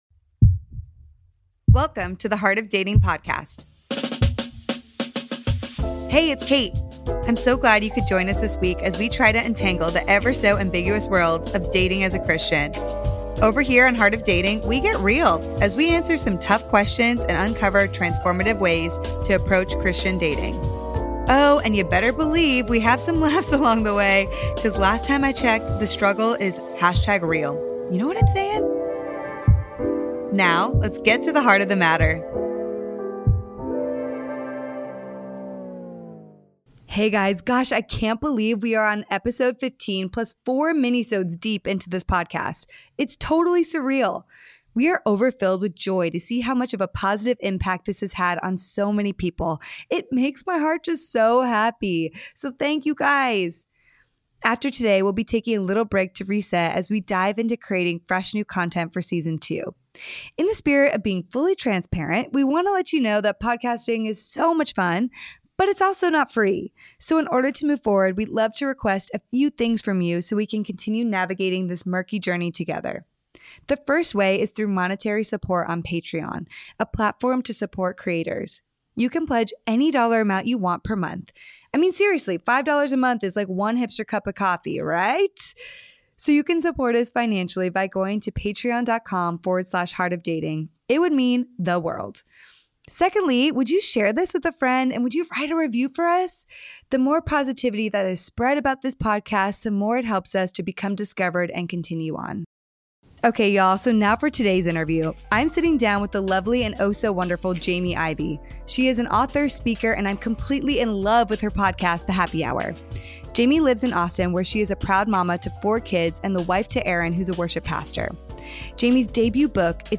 Today, they talk about grace and vulnerability in relationships. This is a super open conversation, insightful, brave and moving.